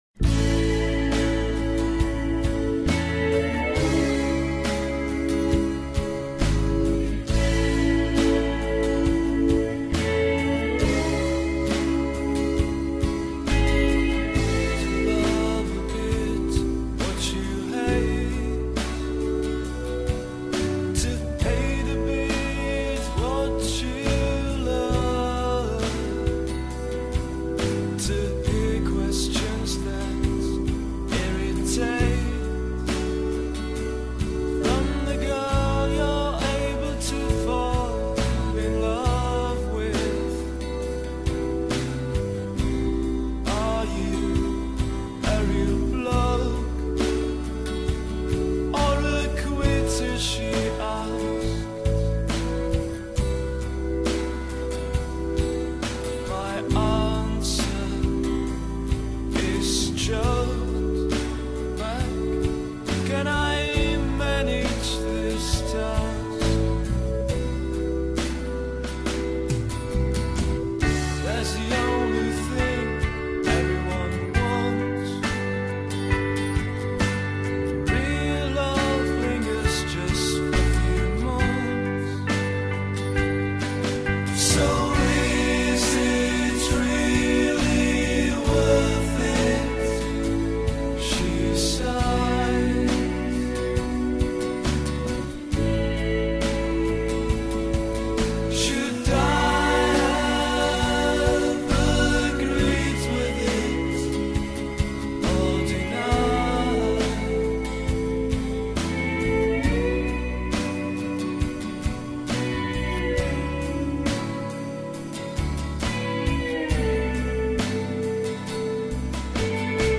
Žánr: Indie/Alternativa
Nahráno během roku 2006 v Praze a Říčanech.